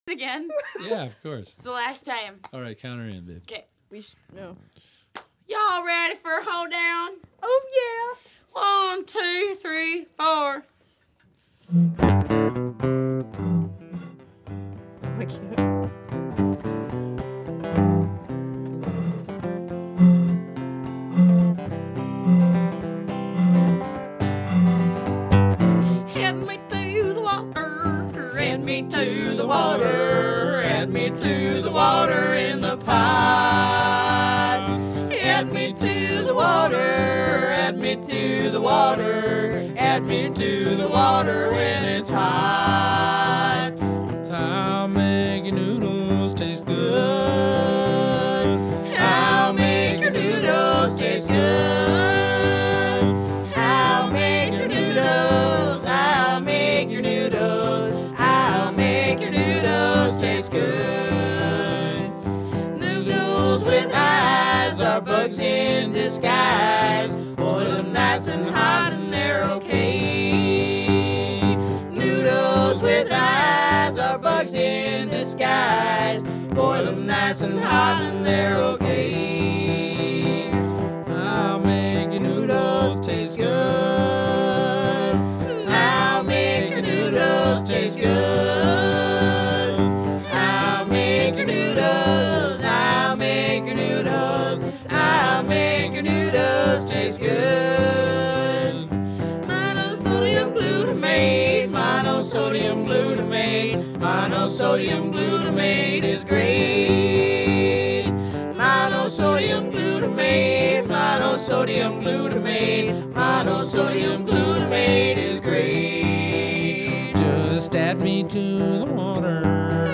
solo overdubbed multitrack recordings
Spontaneous composition and recording.